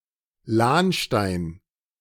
Lahnstein (pronunciación en alemán:
/ˈlaːnˌʃtaɪ̯n/) es un municipio situado en el distrito de Rin-Lahn, en el estado federado de Renania-Palatinado (Alemania).